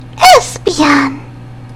infinitefusion-e18/Audio/SE/Cries/ESPEON.mp3 at releases-April